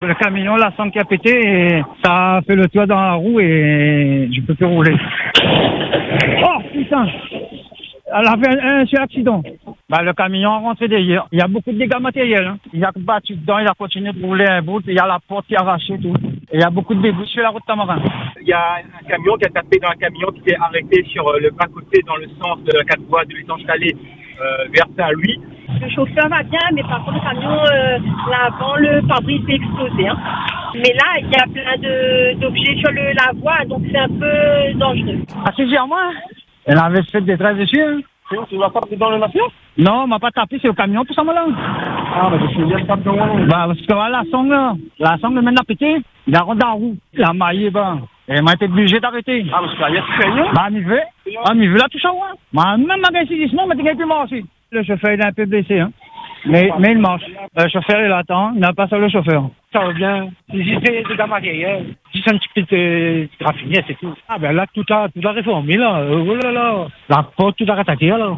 En panne sur la bande d’arrêt d’urgence, un automobiliste nous appelle en direct pour prévenir les autres usagers de la route et signaler sa situation.
Un autre camion vient percuter son véhicule immobilisé. Le choc, la stupeur… et la frayeur en plein direct à l’antenne.